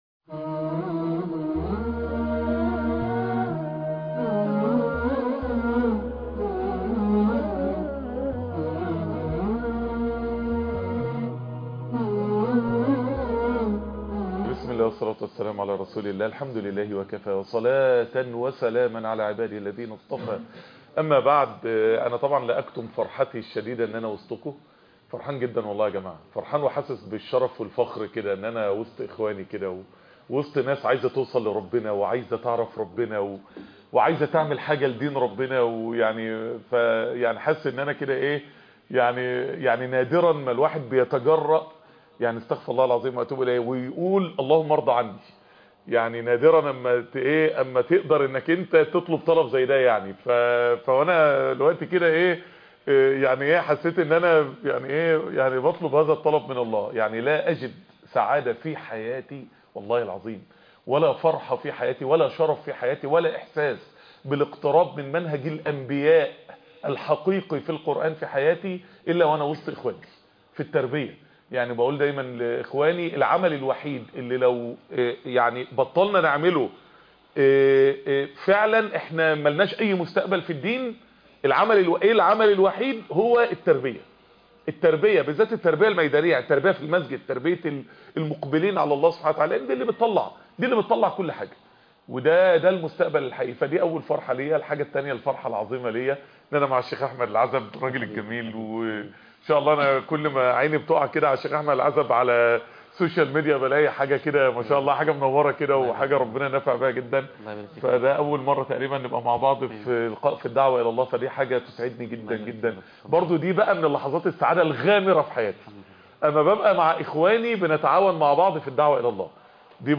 في ظلال سورة الكهف .. محاضرة